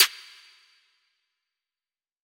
Snare (War).wav